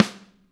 damped snare f.wav